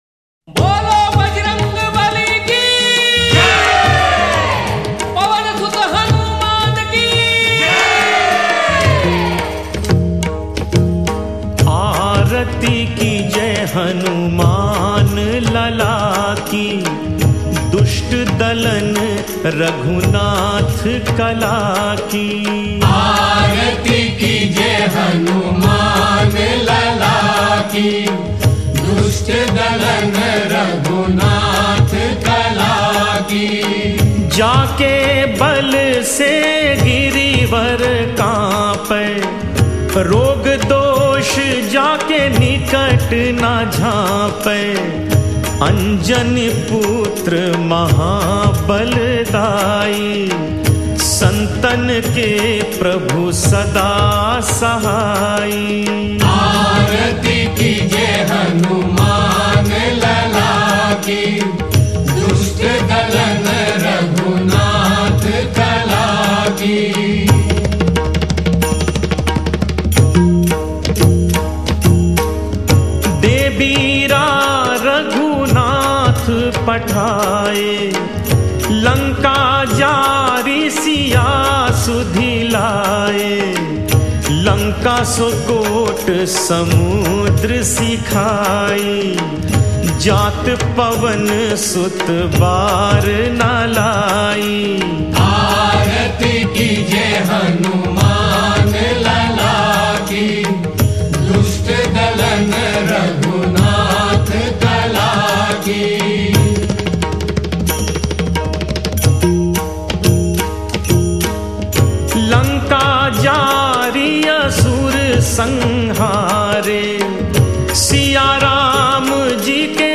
Devotional Songs > Shree Hanuman Bhajans